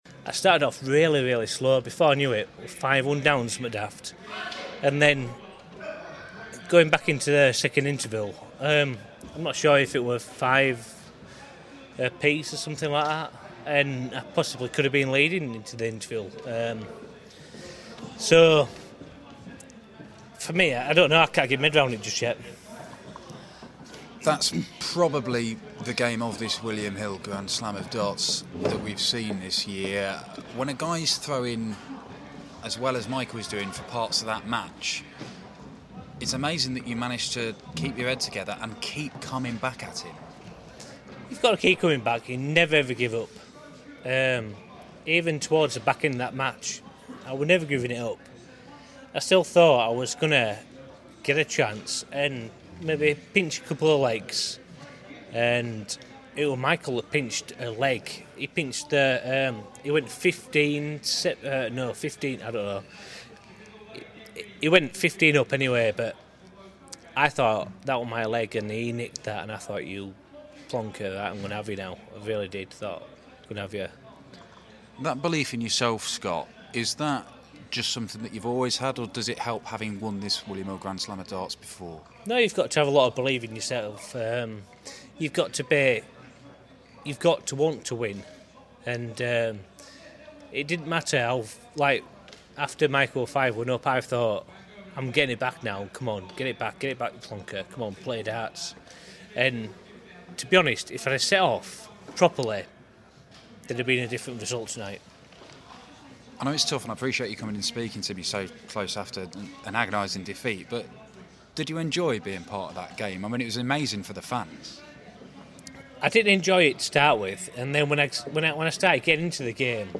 William Hill GSOD - Waites Interview Part One (QF)